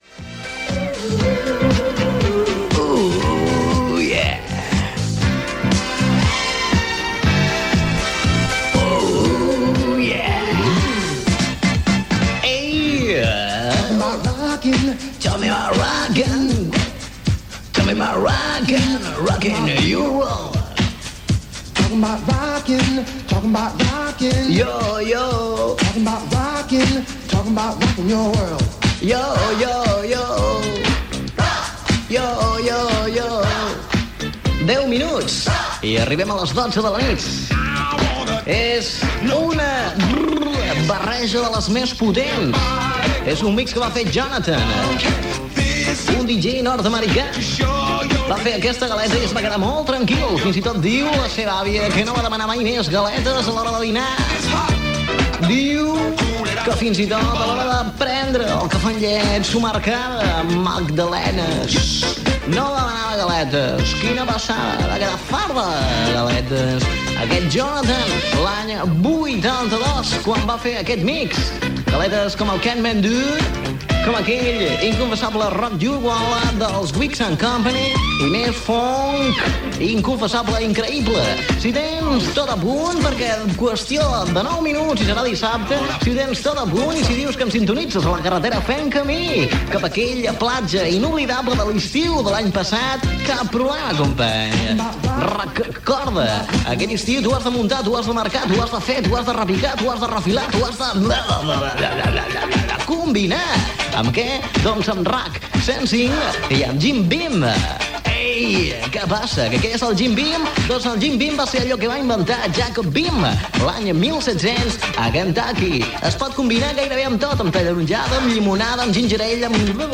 Musical
FM